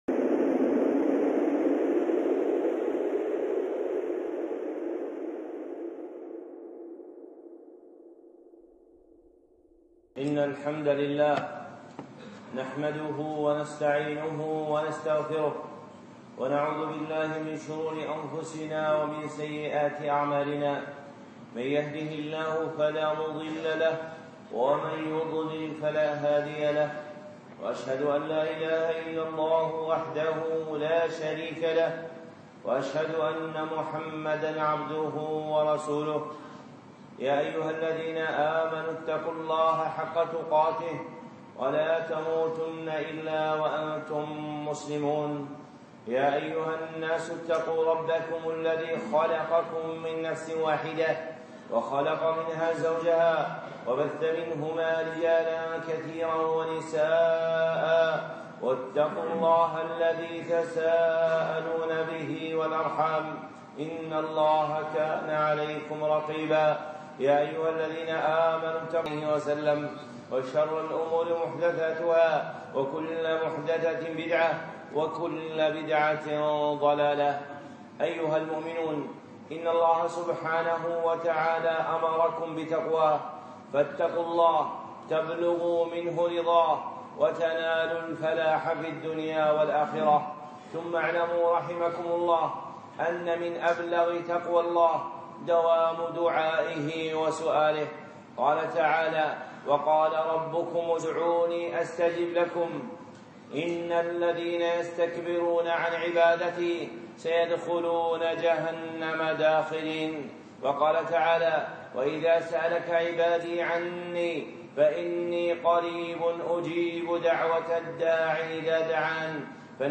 خطبة (ملعون من سأل بوجه الله)